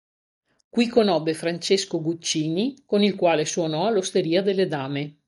Read more Det Pron Adv Frequency A1 Hyphenated as quà‧le Pronounced as (IPA) /ˈkwa.le/ Etymology From Latin quālis.